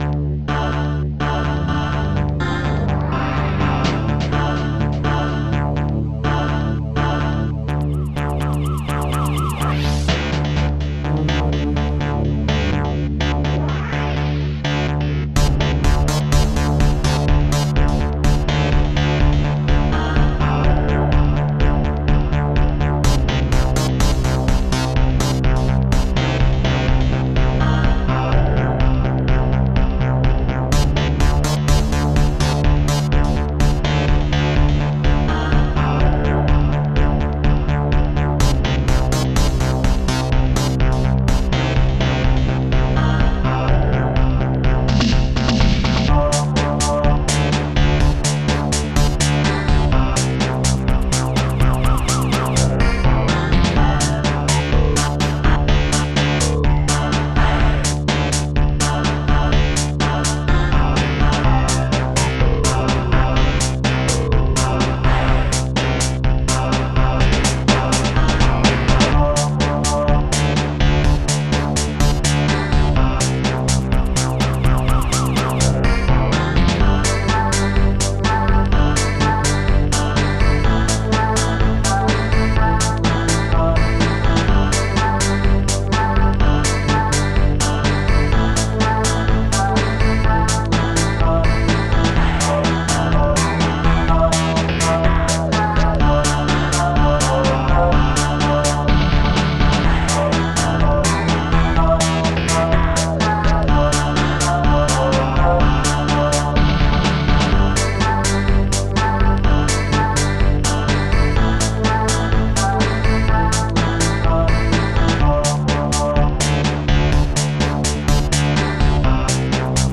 st-07:Hardsynth
st-07:moog1
st-07:sirene
st-01:BassDrum3
st-01:Strings4
st-06:snare-4
st-06:hihat-3